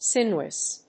発音記号
• / sínjuəs(米国英語)